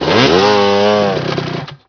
sawstart.wav